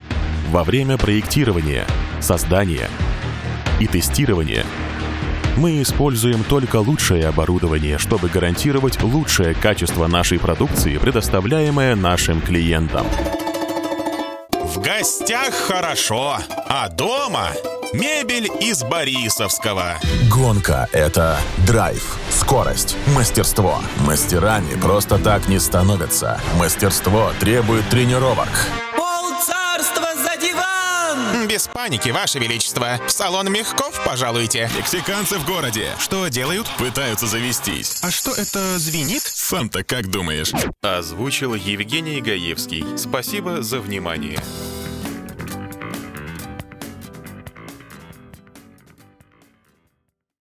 Статус: Диктор доступен для записи.
• Кабина + Focusrite 2i2 3rd gen + dbx 286s + Rode NTG3b • Качественные кабели, тихое помещение, ничего не шумит.